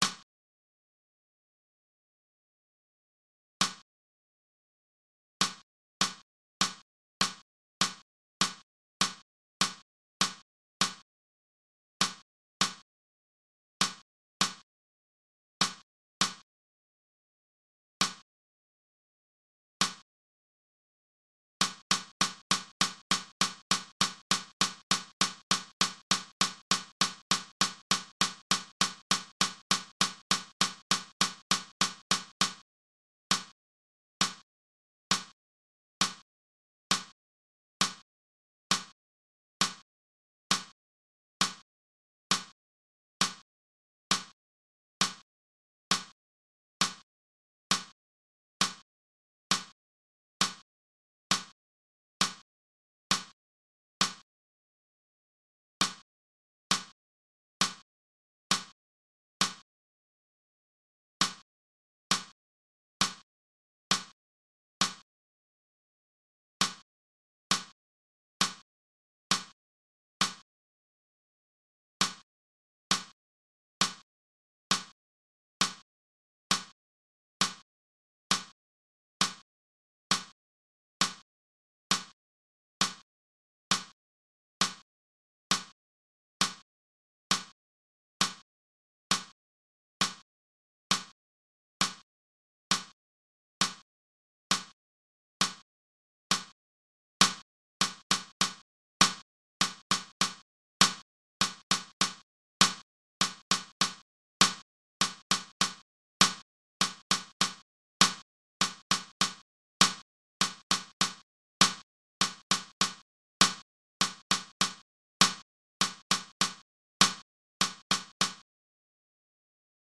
8分の9拍子音源.mp3